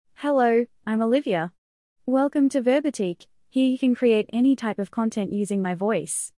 OliviaFemale Australian English AI voice
Olivia is a female AI voice for Australian English.
Voice: OliviaGender: FemaleLanguage: Australian EnglishID: olivia-en-au
Voice sample
Listen to Olivia's female Australian English voice.
Olivia delivers clear pronunciation with authentic Australian English intonation, making your content sound professionally produced.